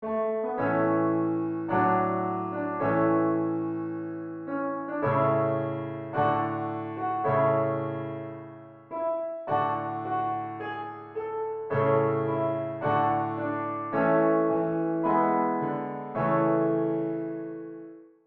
Higher key
Psalm-103b-Audio-Higher.wav